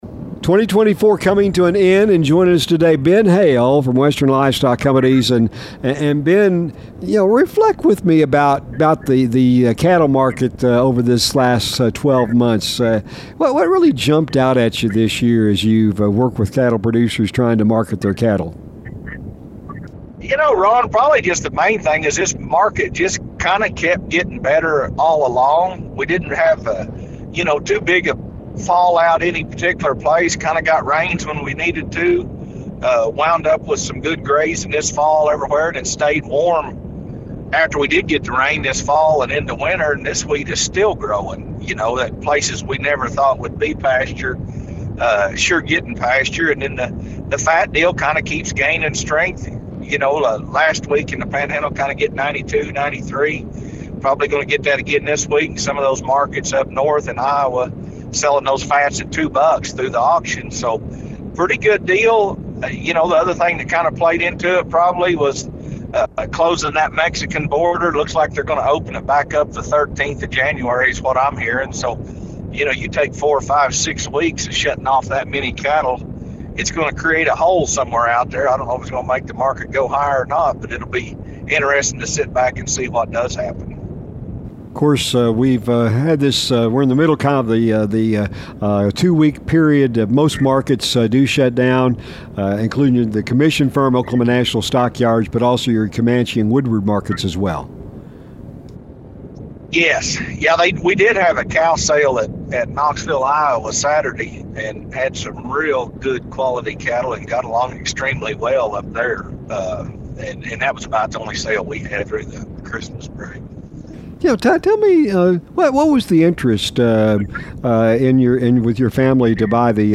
Today's conversation comes between the Christmas and New Year's holidays and offers a review of the old year and a look into 2025.